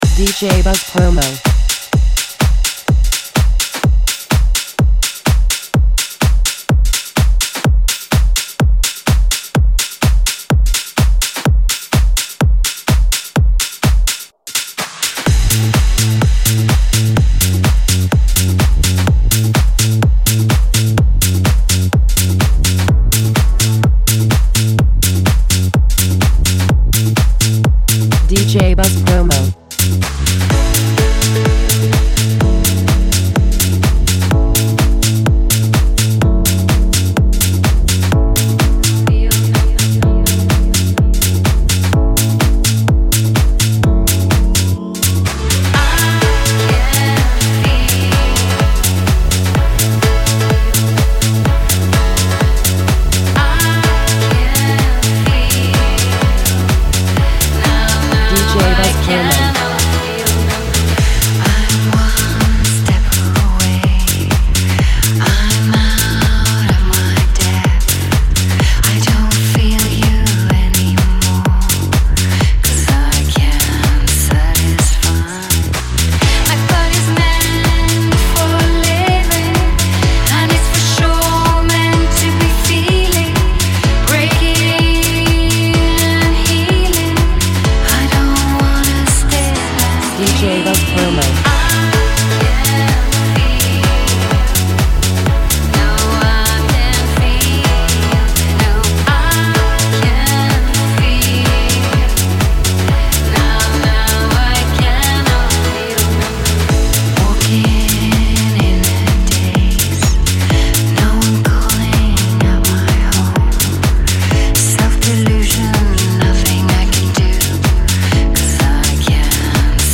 the anthemic main mix
classic house vibes